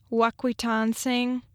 (wek-wə-TON-sing)